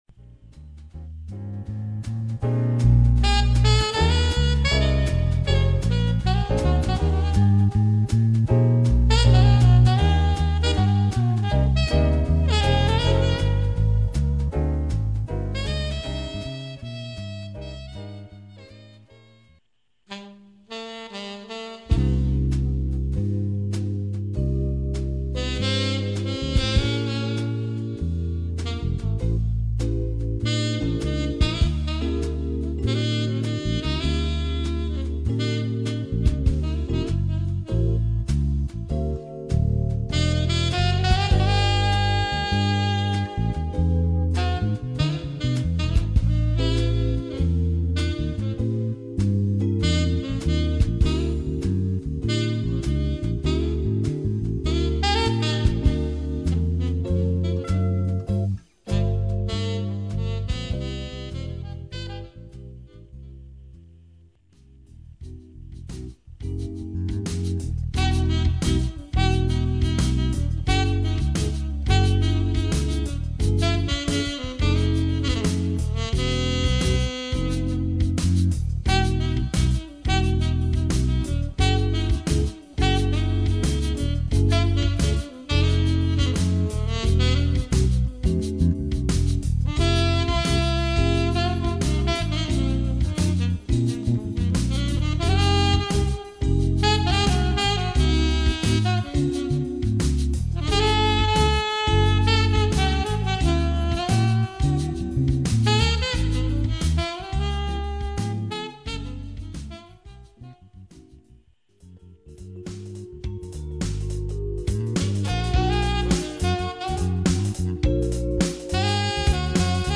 The group performs as a two piece duo, trio, or quartet and can even add a vocalist. Superb musicianship, professionalism and courtesy have made Mirage one of the area's most sought after jazz groups.